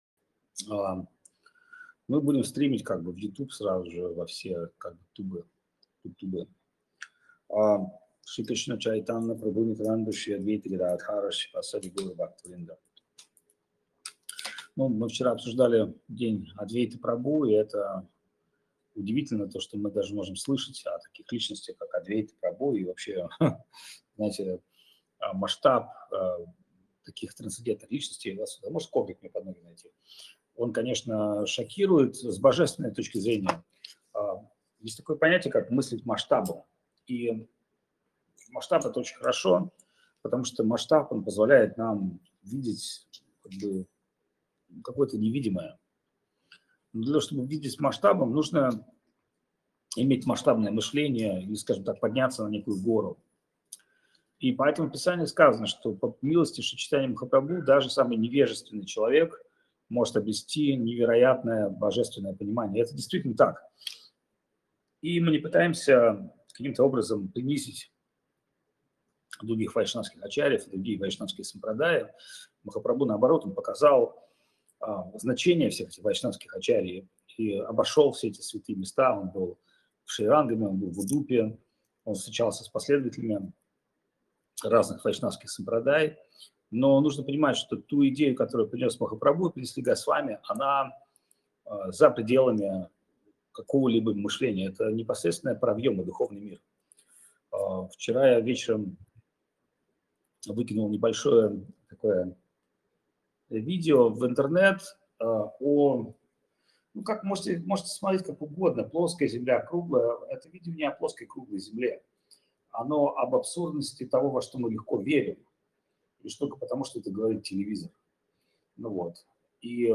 Лекции полностью
Проповедь